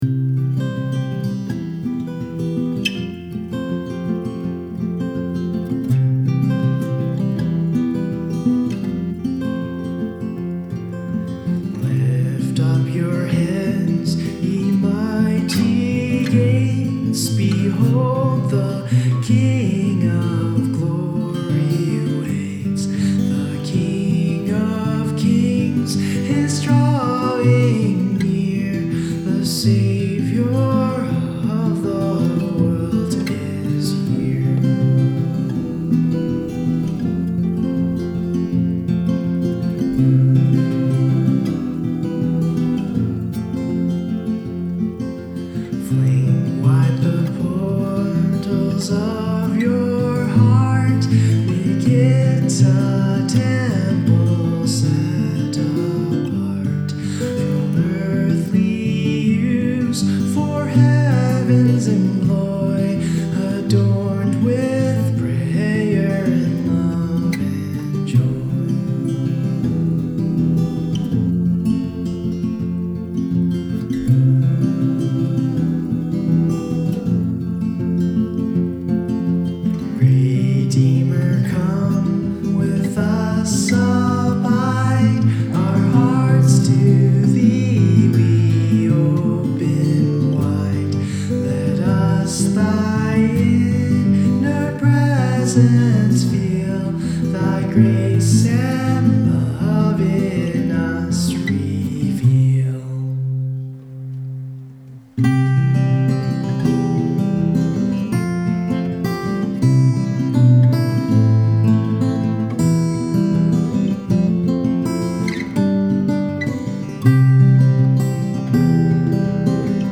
I do mainly folk/acoustic/re-tuned hymns/etc. My...
My current set-up is: ART MSIX condenser mic --> Steinberg CI1 Audio Interface --> GarageBand (Also have a Ear Trumpet Louise Mic) Here is a recording I did with this set-up View attachment Lift Up Your Heads - updated.mp3 I want to get a little more serious and attempt to make better recordings.